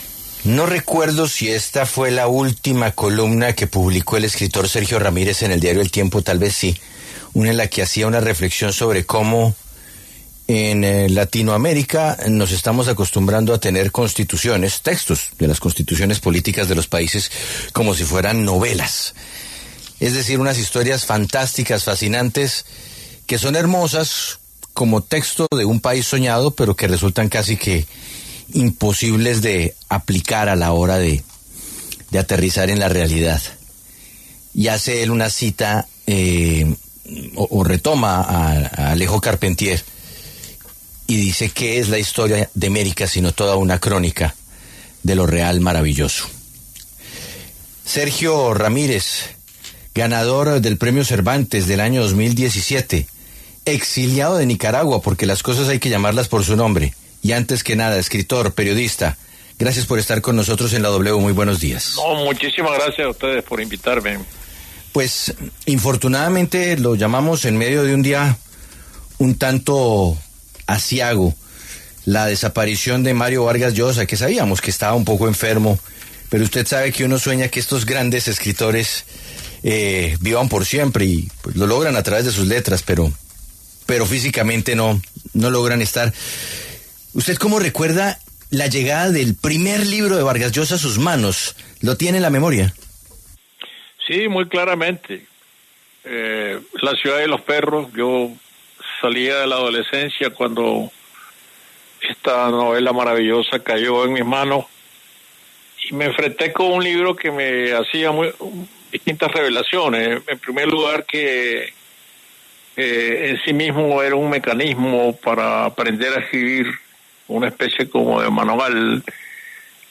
En diálogo con La W, el escritor Sergio Ramírez habló sobre su amistad personal con Mario Vargas Llosa y el legado de su obra, al ser considerado el último patriarca del boom latinoamericano.